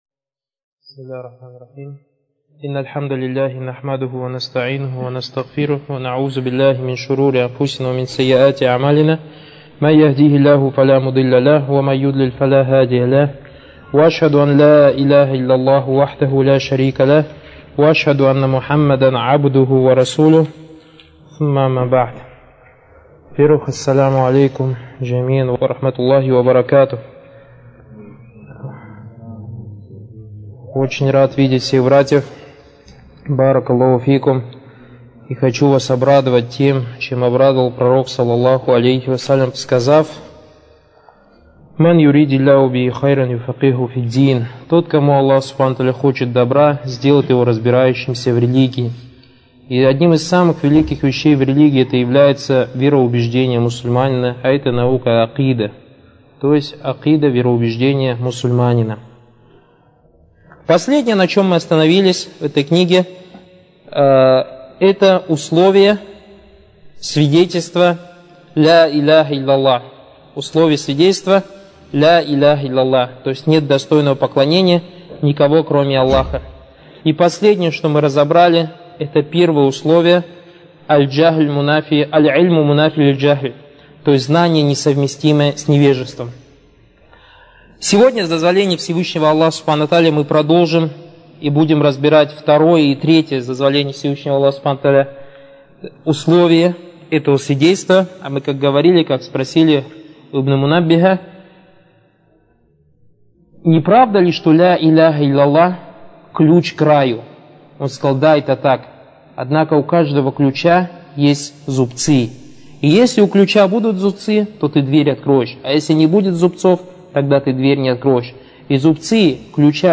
Материал: лекции